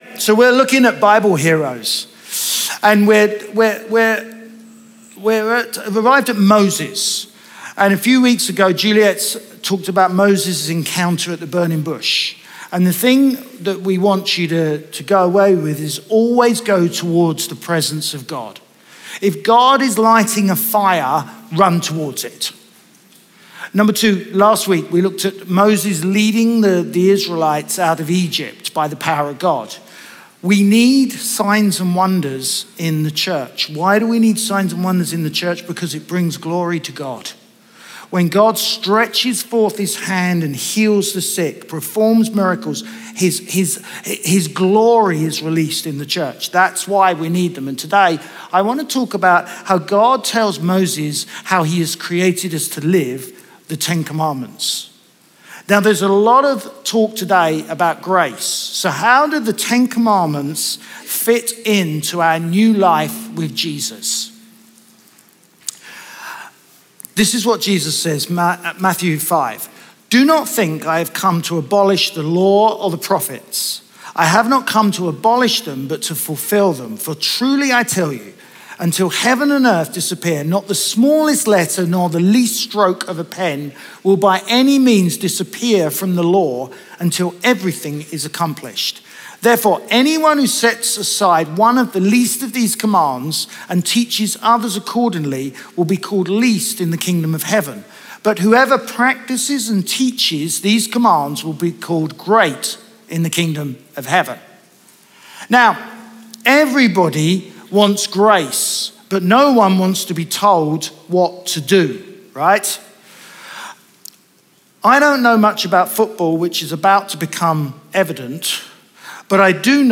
Chroma Church - Sunday Sermon Moses' 10 commands Mar 30 2023 | 00:26:58 1x 00:00 / 00:26:58 Subscribe Share RSS Feed Share Link Embed